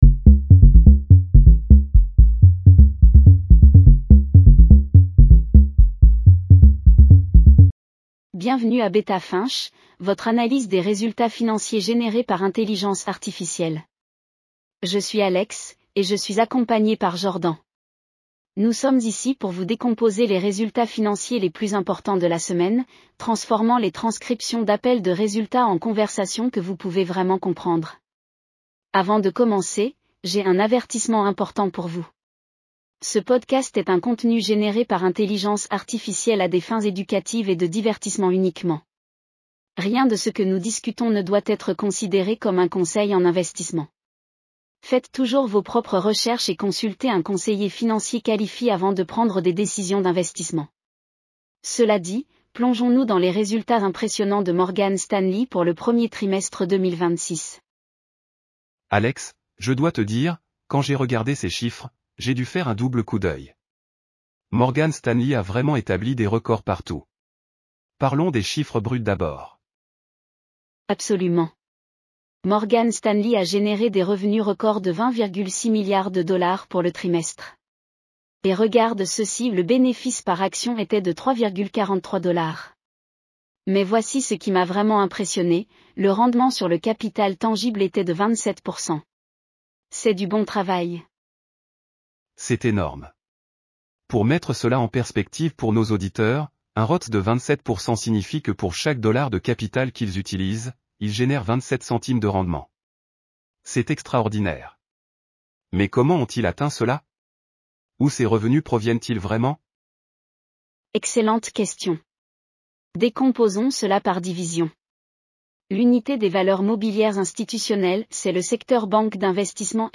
Bienvenue à Beta Finch, votre analyse des résultats financiers générée par intelligence artificielle.